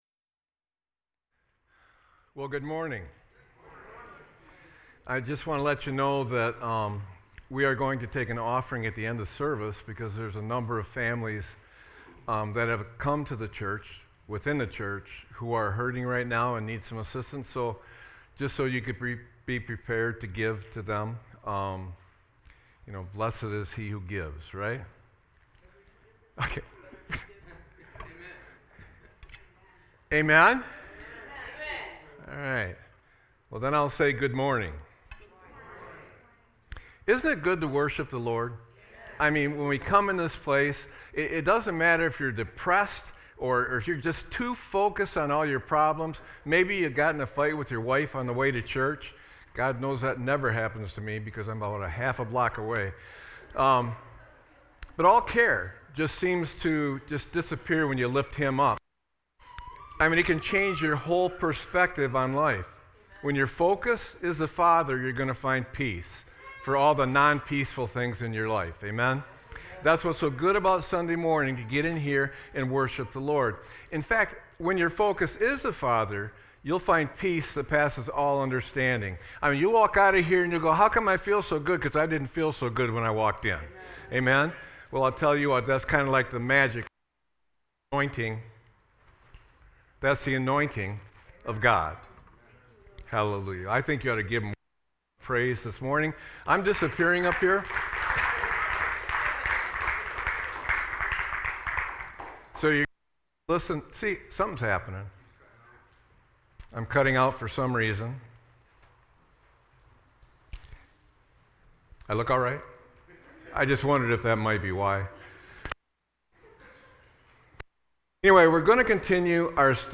Sermon-2-02-25.mp3